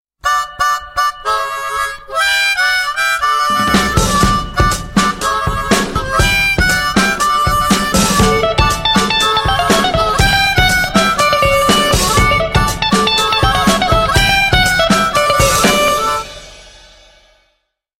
• Качество: 128, Stereo
Забавная мелодия губной гармошки на звонок вашего телефона